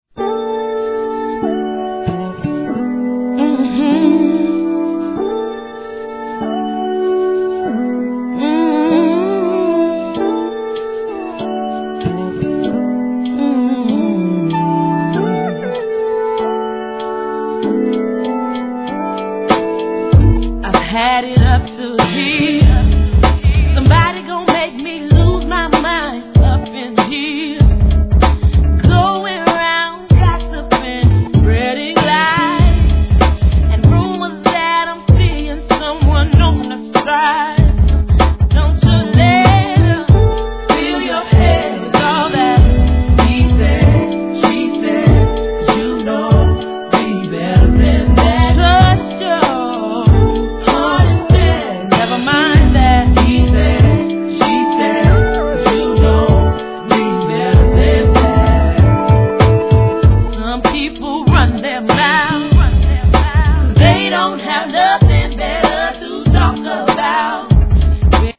HIP HOP/R&B
全体的に心地よいMID〜UPで聴かせます!!